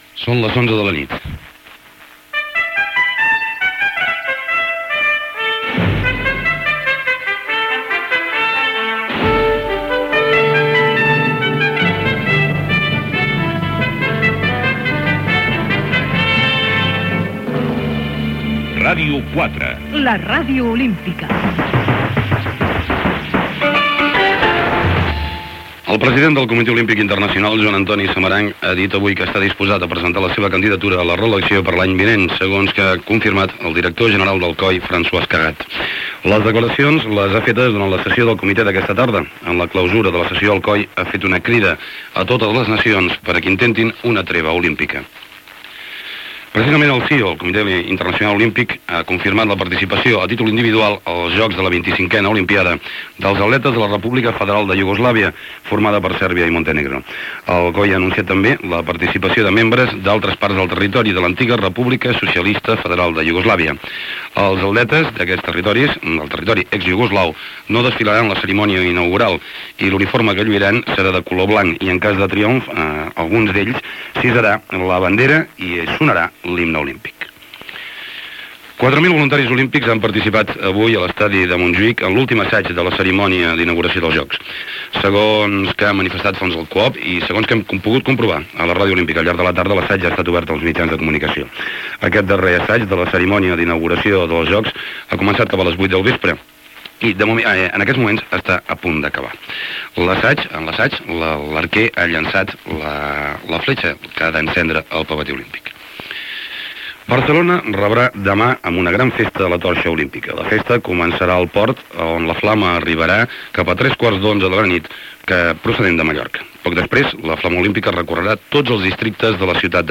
Gènere radiofònic Informatiu
Banda FM